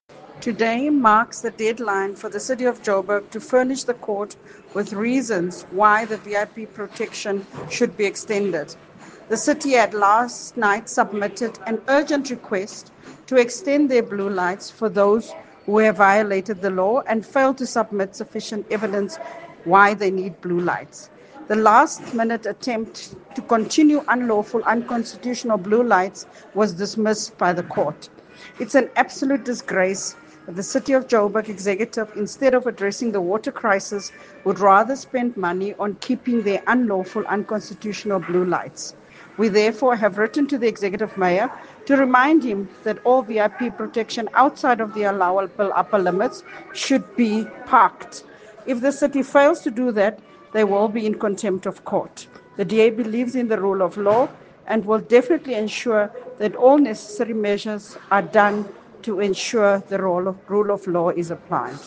Note to Editors: Please find an English soundbite by Cllr Belinda Kayser-Echeozonjoku